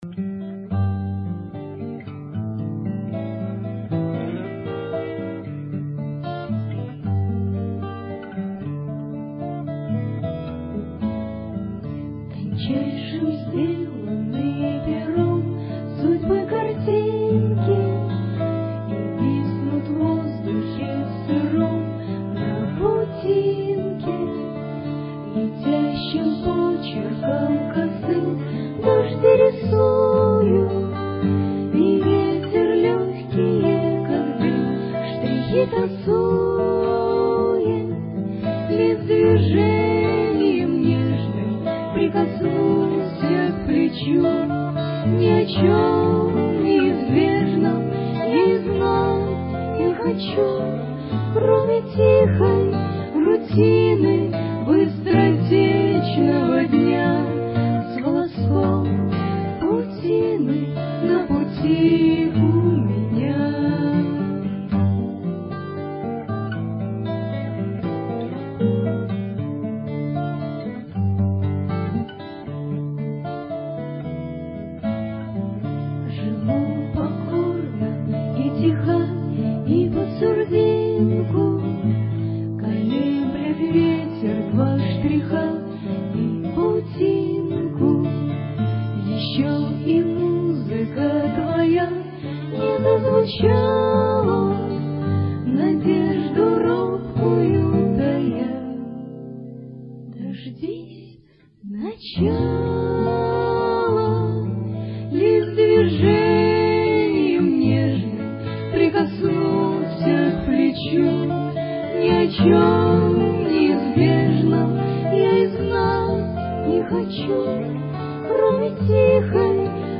Красивый вальс
Тут - два сопрано, а там - другие голоса...